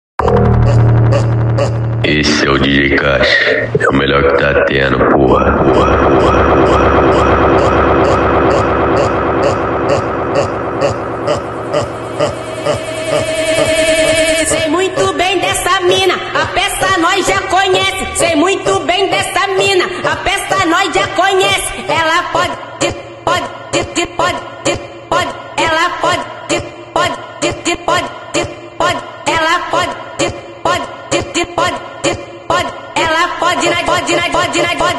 Жанр: Фанк